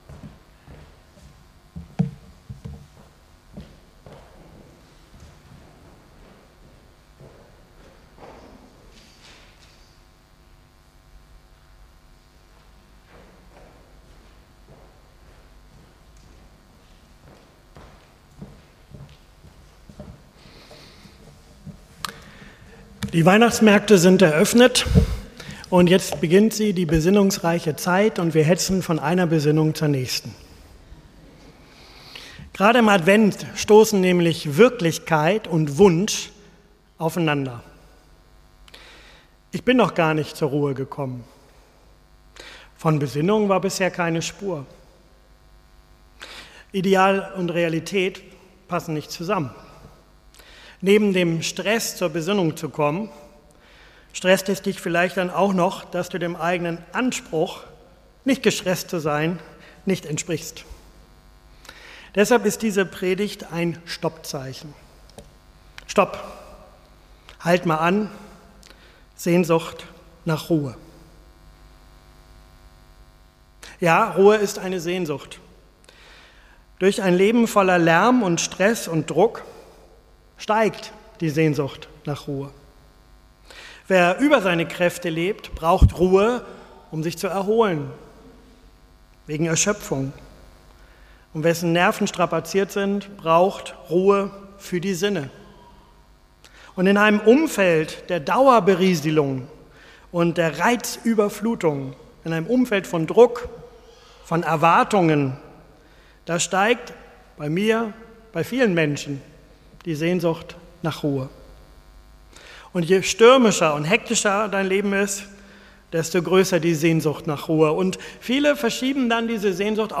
Predigtreihe: Sehnsüchte Bibeltext: Jesja 30,15 Predigt am 1. Advent 2025